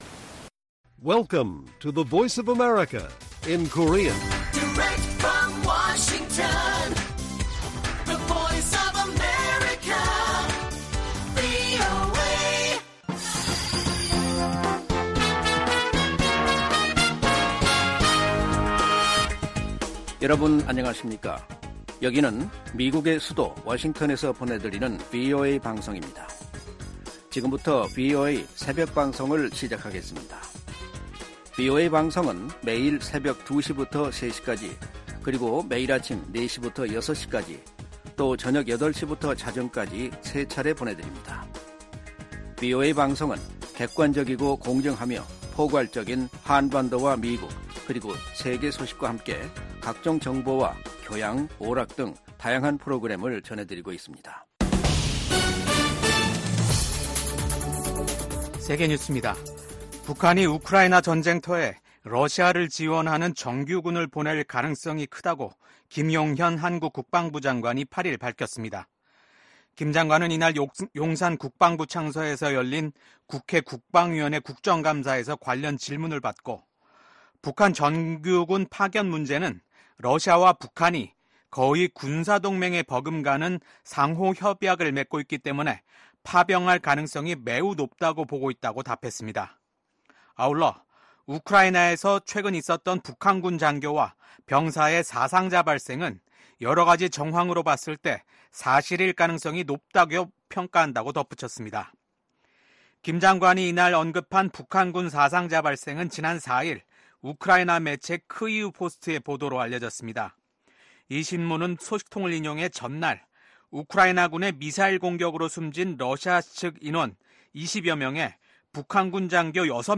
VOA 한국어 '출발 뉴스 쇼', 2024년 10월 9일 방송입니다. 김정은 북한 국무위원장은 적들이 무력 사용을 기도하면 주저없이 핵무기를 사용할 것이라고 위협했습니다. 미국 정부가 북한 해킹조직 라자루스가 탈취한 가상 자산을 압류하기 위한 법적 조치에 돌입했습니다.